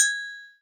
Percs
Metallic Rnb.wav